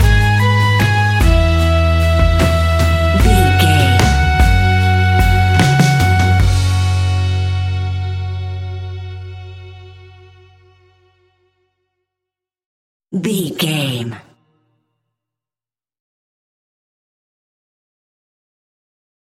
Uplifting
Ionian/Major
G♭
acoustic guitar
mandolin
ukulele
lapsteel
drums
double bass
accordion